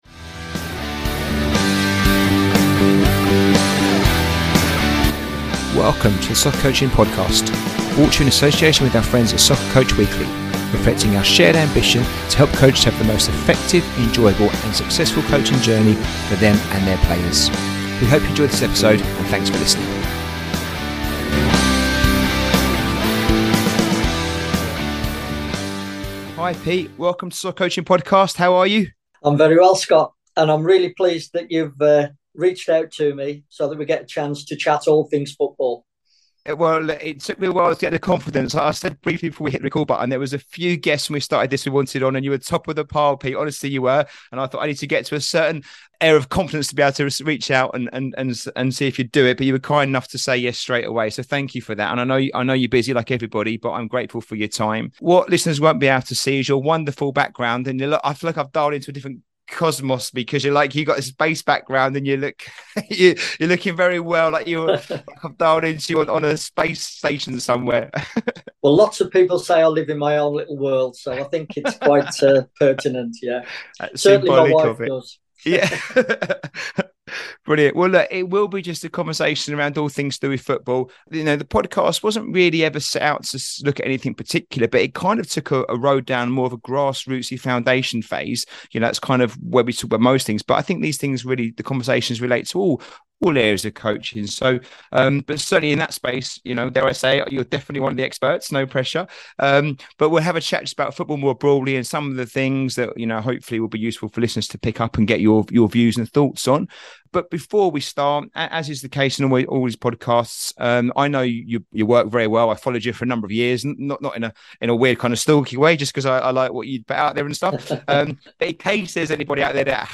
Episode 85 - Foundation Phase Player Development, a conversation